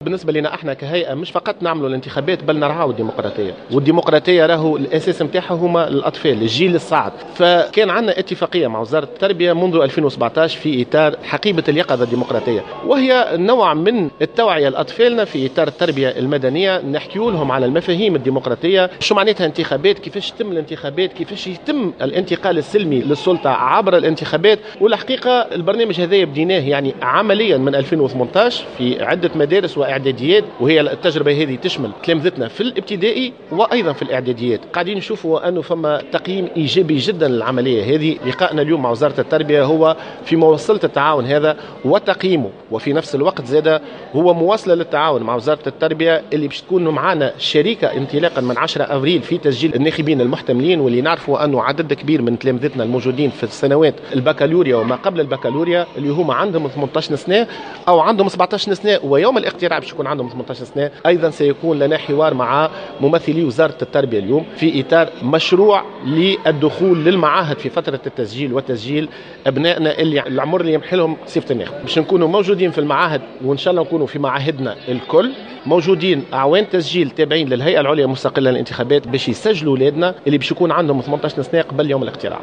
وأكد بفون اليوم السبت في تصريح لمراسلة الجوهرة اف ام خلال ندوة صحفية حول مشروع حقيبة اليقظة الديقراطية، أنه سيتم تسجيل التلاميذ الذين بلغت أعمارهم 18 سنة وكذلك الذين سيبلغون هذا السن خلال فترة التسجيل وقبل موعد الانتخابات حتى بيوم واحد.